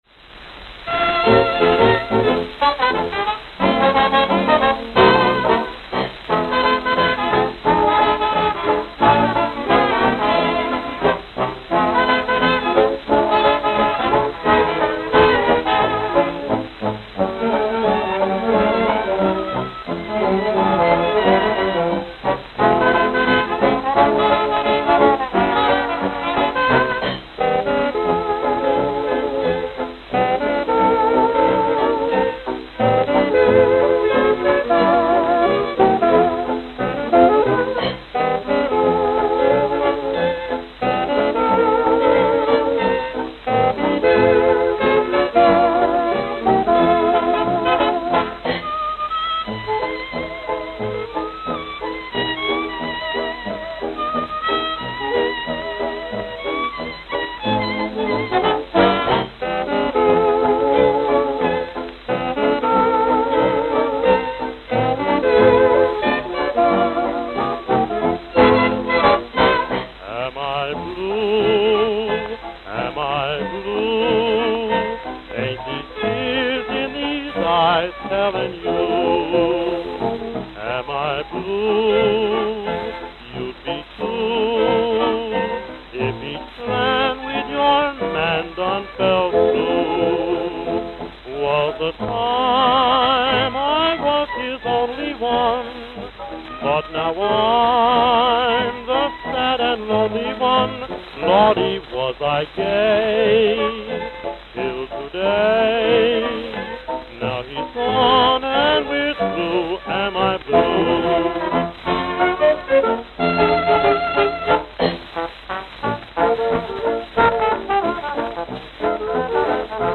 Note: Worn.